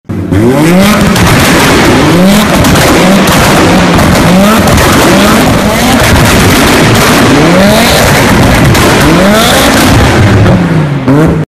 Звук работающей выхлопной системы Chevrolet Blazer